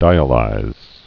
(dīə-līz)